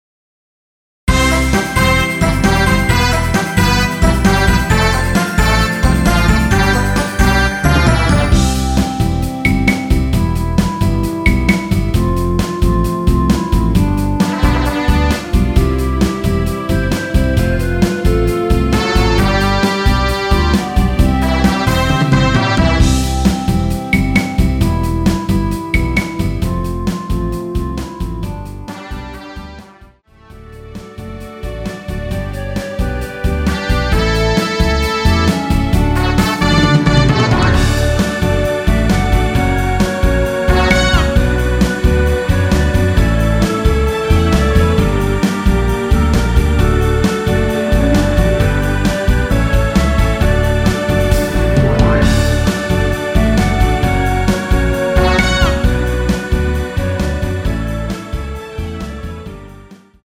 원키에서(-5)내린 멜로디 포함된 MR입니다.
앞부분30초, 뒷부분30초씩 편집해서 올려 드리고 있습니다.
(멜로디 MR)은 가이드 멜로디가 포함된 MR 입니다.